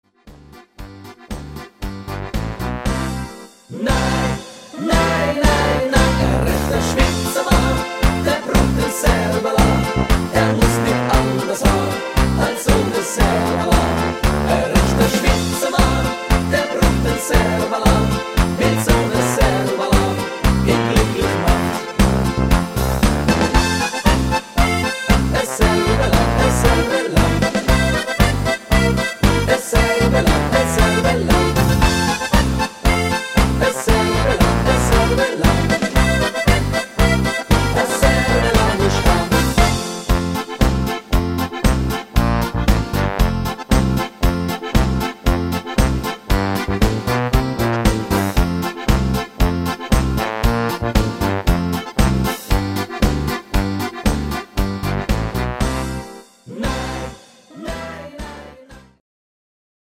Schweizer Stimmungshit